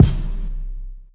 stacked_kick_2.wav